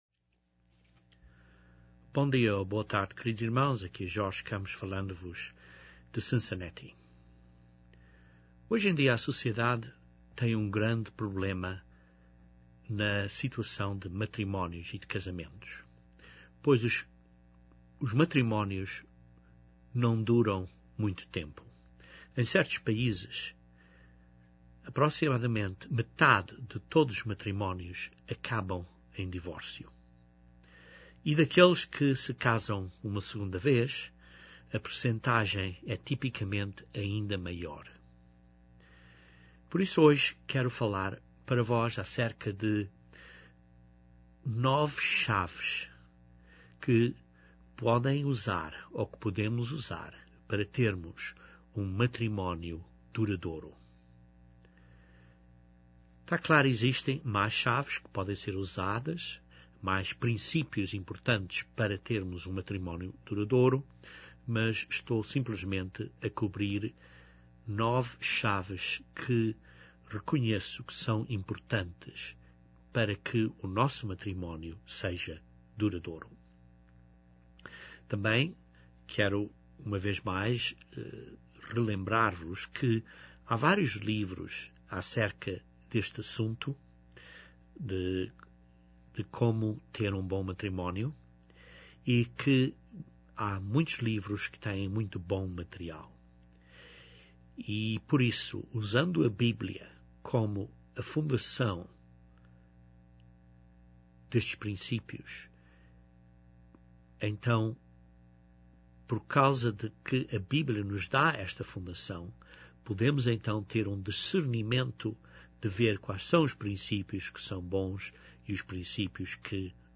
Este sermão meramente descreve nove princípios bíblicos para um matrimónio duradouro.